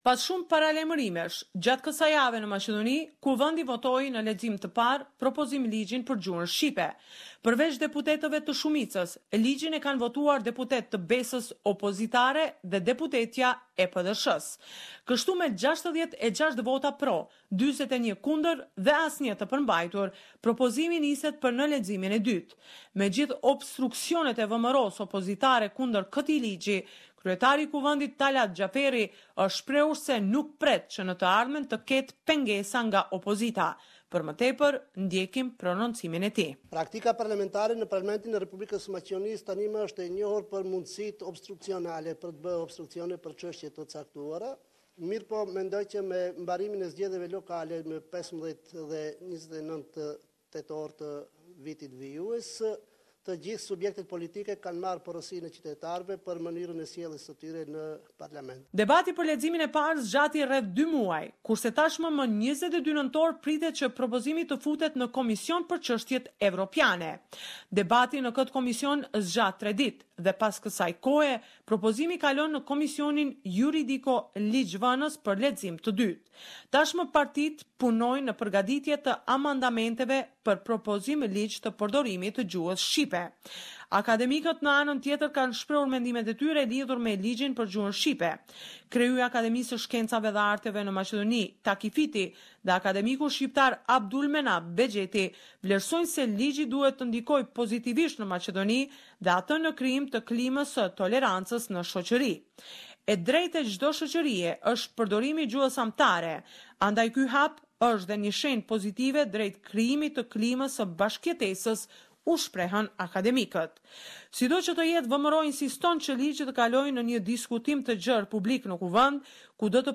This is a report summarising the latest developments in news and current affairs in FYROM.